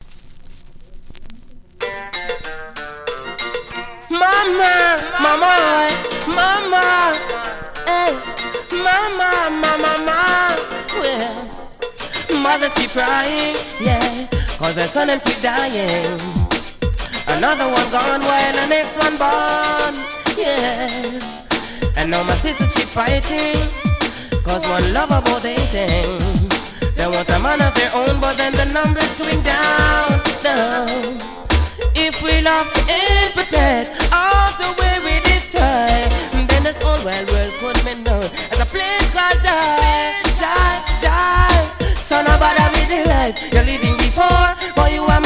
• Style: Dancehall